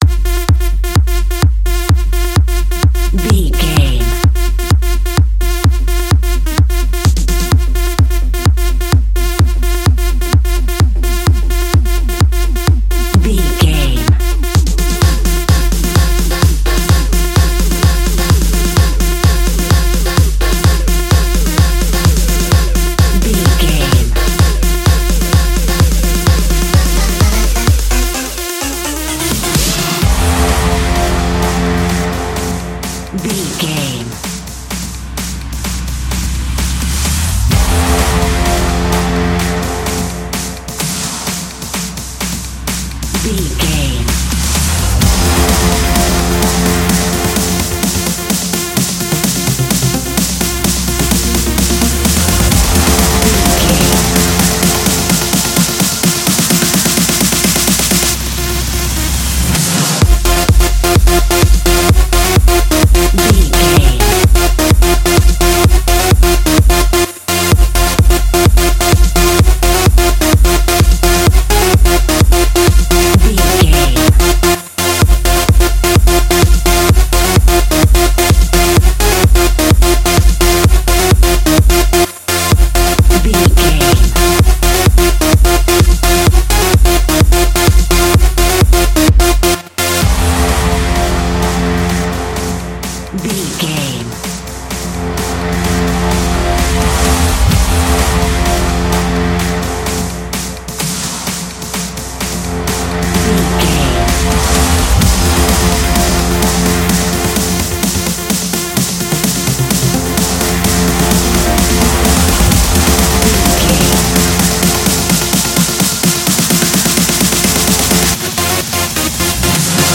Aeolian/Minor
Fast
energetic
hypnotic
uplifting
synthesiser
drum machine
acid house
uptempo
synth leads
synth bass